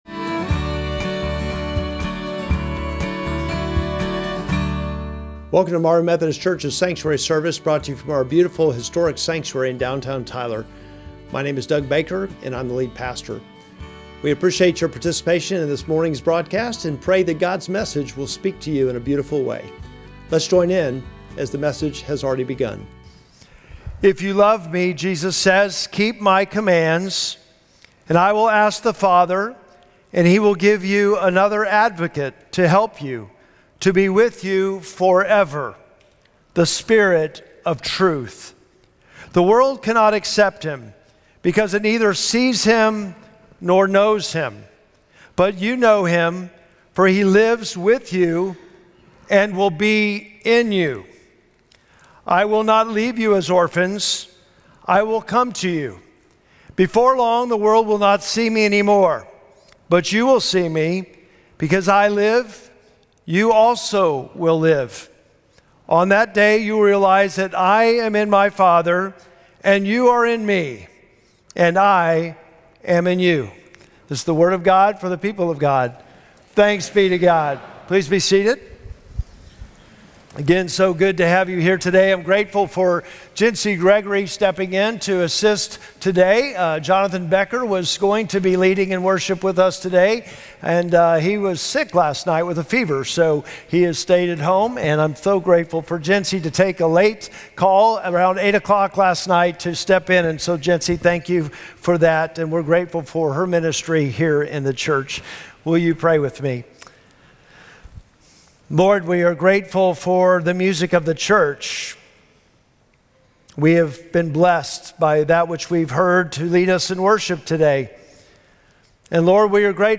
Sermon text: John 14:15-20